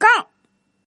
Index of /client/common_mahjong_tianjin/mahjongjinghai/update/1124/res/sfx/tianjin/woman/